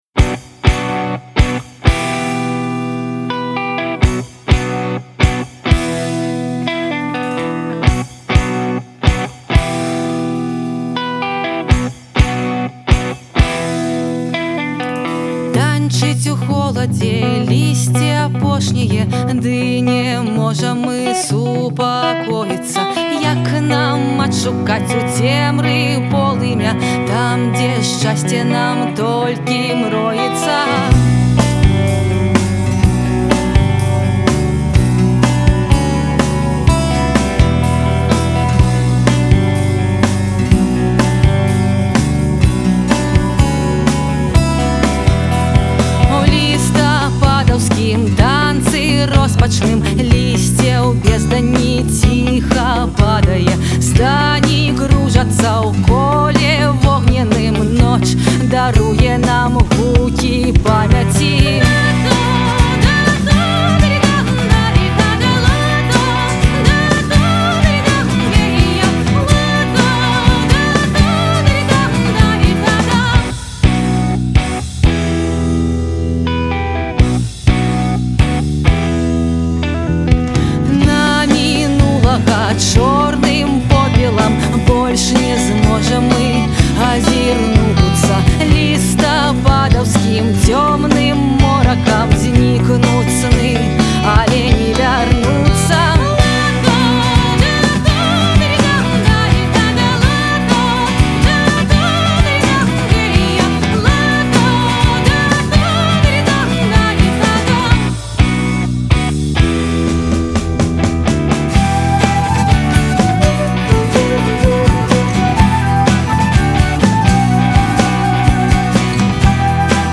Стыль - нэафольк, акустычны фольк.